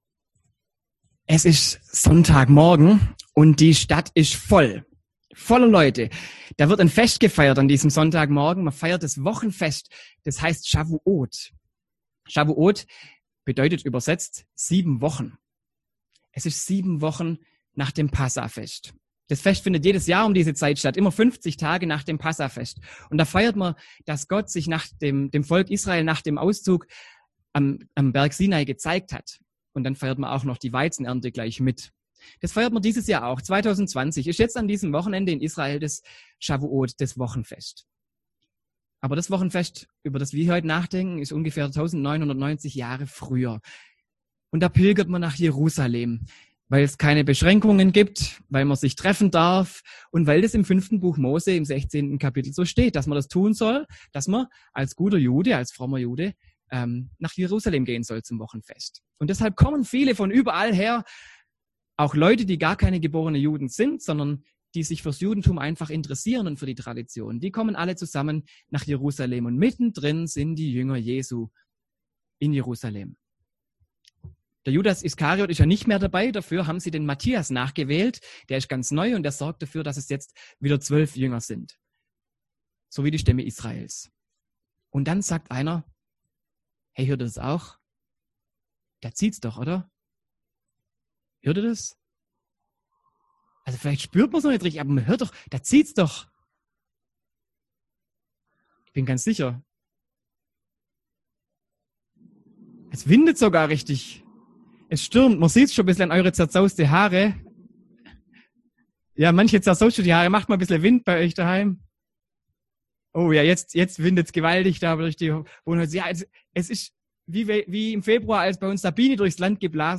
Predigt an Pfingsten